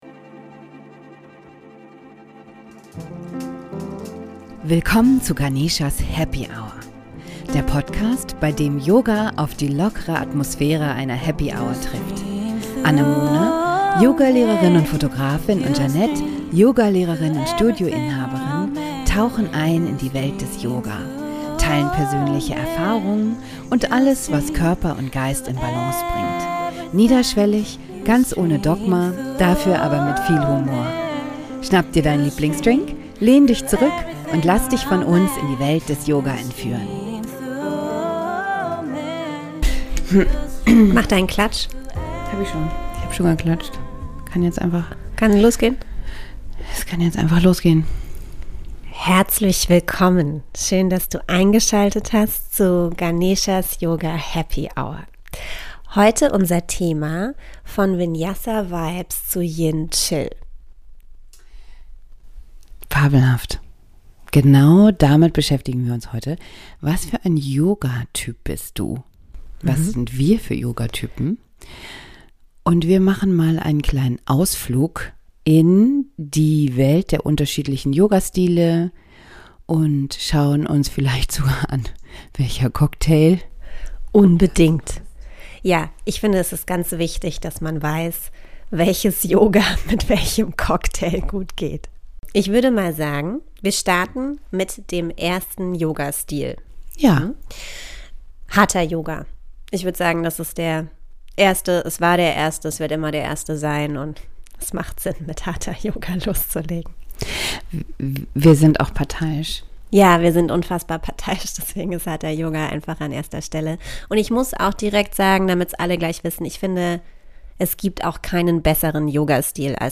Welcher Cocktail matcht mit welchem Yogatyp Und warum du nicht nur ein Yoga-Typ sein musst Ein lockeres Gespräch für alle, die neugierig auf Yoga sind – oder sich mal wieder selbst ein bisschen besser kennenlernen möchten.